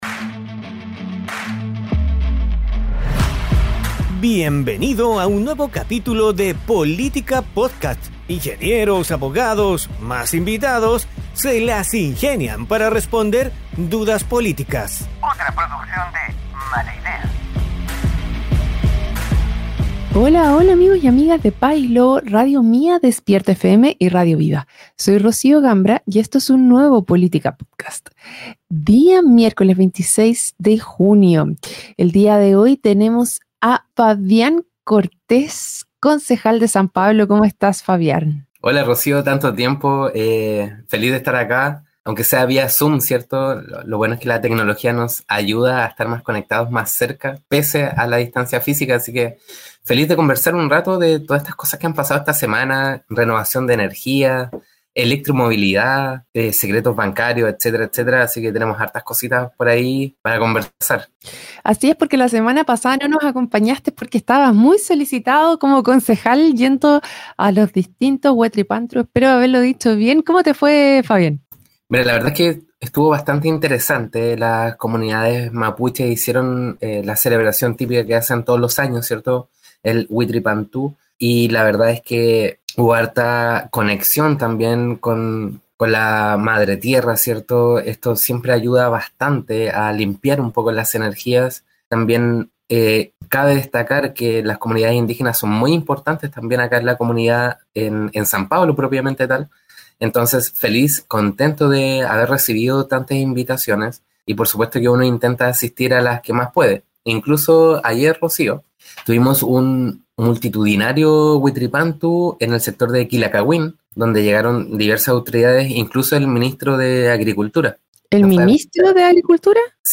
En el episodio de hoy, conversamos con Fabián Cortez, concejal de San Pablo, sobre temas relevantes para la comunidad y el país.